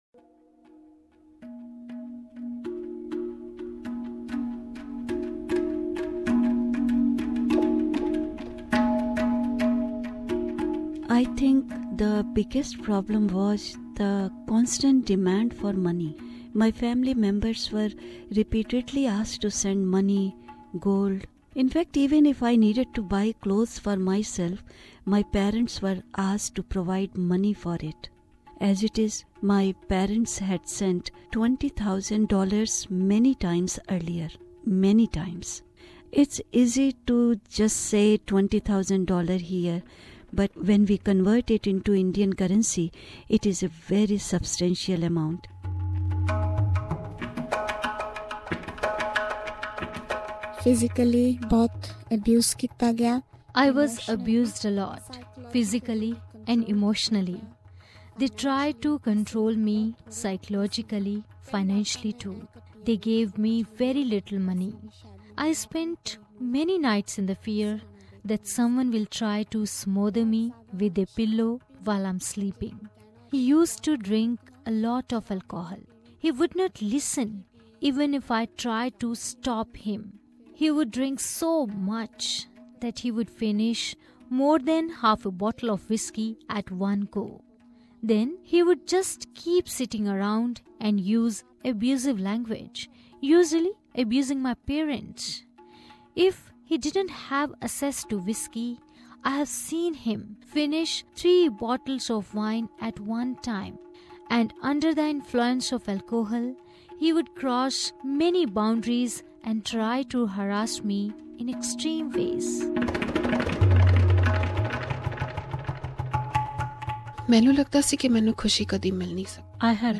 Here is the English version of the feature which was originally produced in Punjabi.